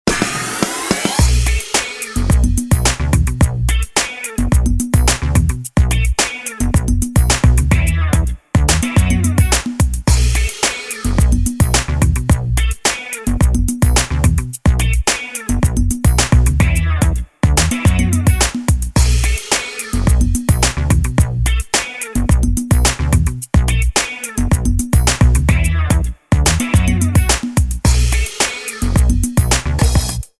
Observação atual Trilha de fundo em anexo.
trtestemunhal30s.wav